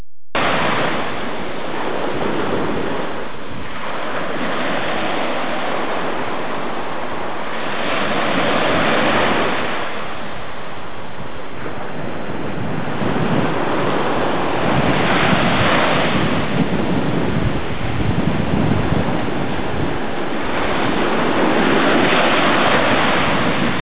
ocean_waves.au